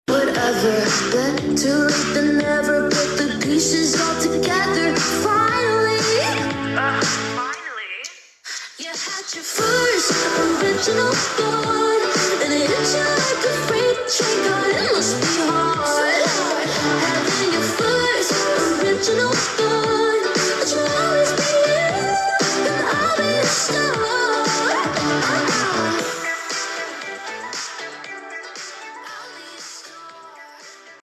the adlibs and high note im so obsessed